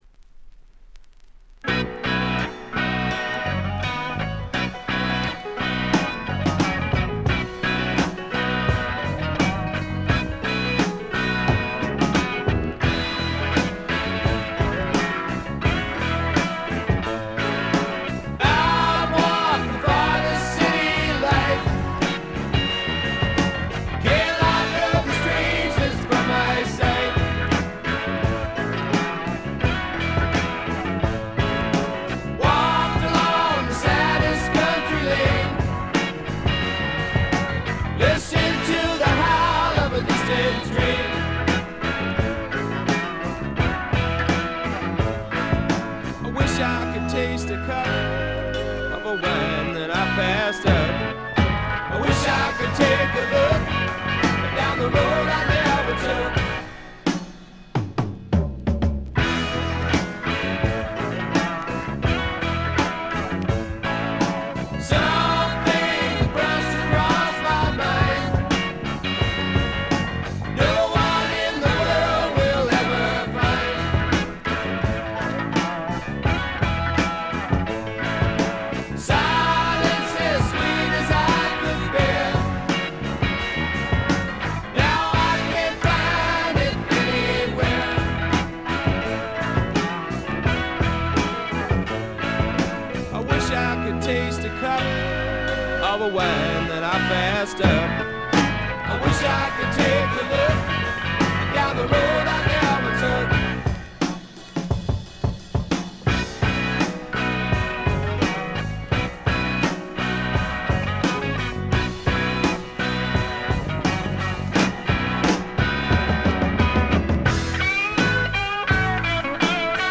静音部での軽微なチリプチ程度。
試聴曲は現品からの取り込み音源です。